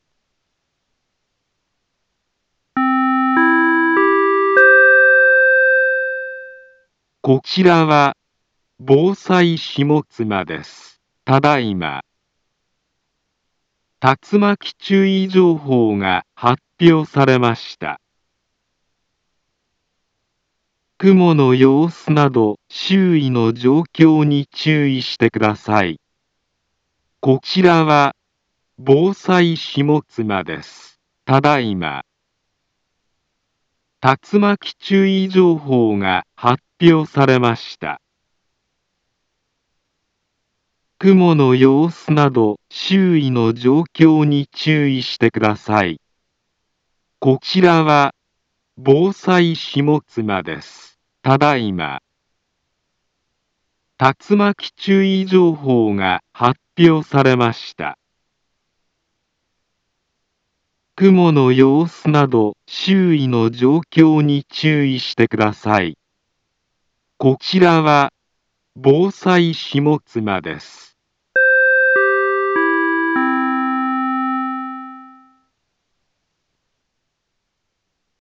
Back Home Ｊアラート情報 音声放送 再生 災害情報 カテゴリ：J-ALERT 登録日時：2023-06-28 17:09:51 インフォメーション：茨城県南部は、竜巻などの激しい突風が発生しやすい気象状況になっています。